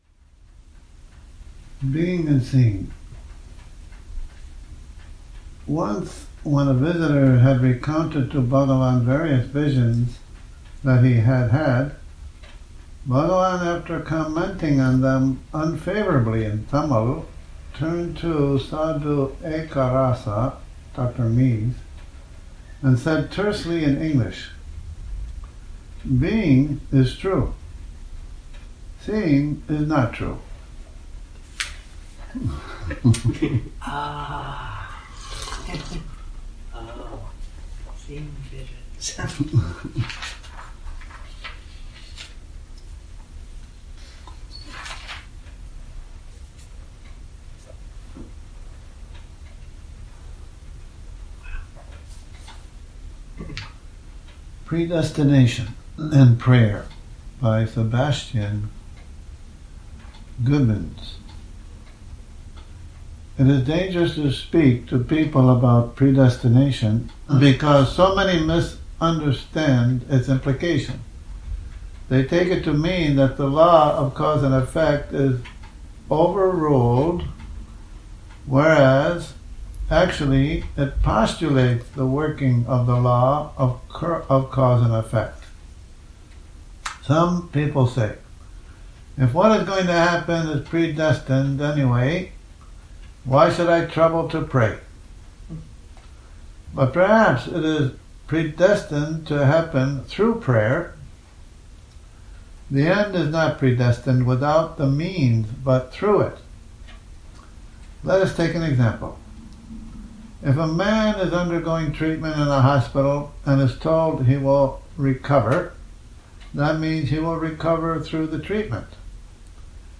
Morning Reading, 29 Nov 2019